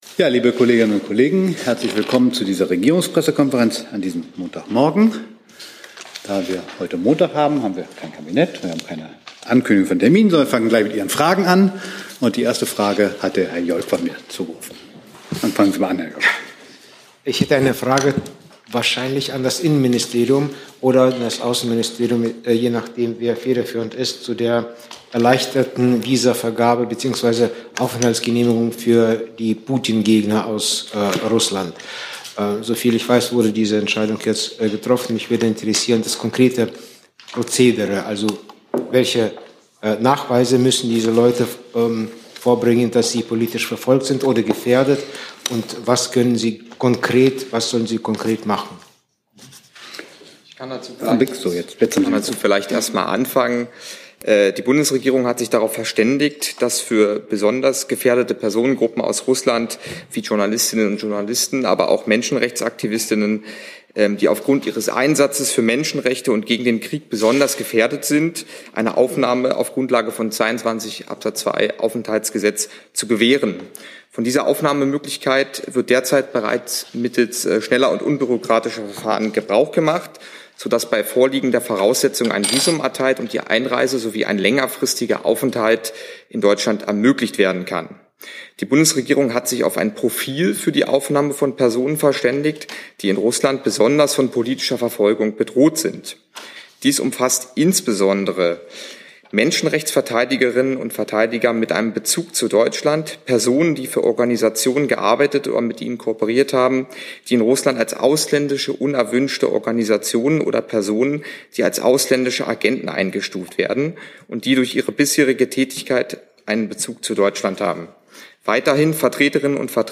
Regierungspressekonferenz in der BPK vom 30. Mai 2022